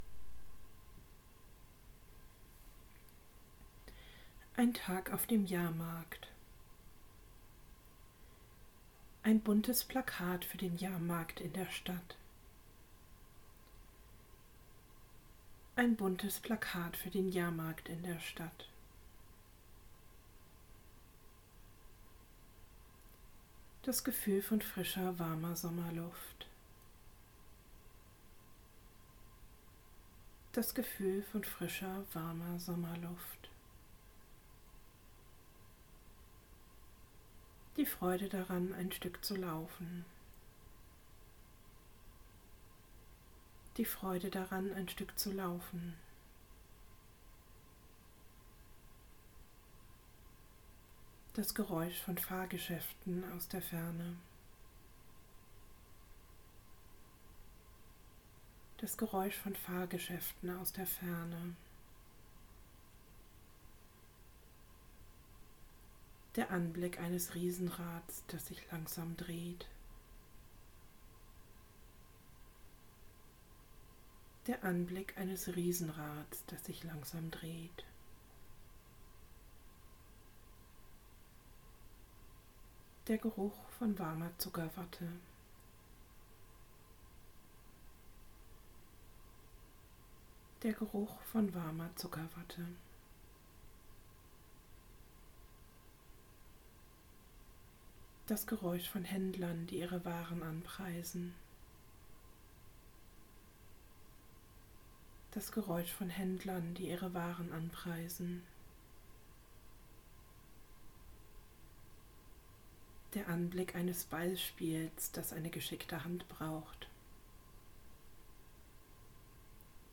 In dieser Kurzversion bekommt ihr Anleitung, euch eine Sinneserfahrung nach der anderen vorzustellen. Die Anleitung wird zweimal wiederholt und dann werdet ihr direkt zum nächsten Bild oder Sinn übergeleitet.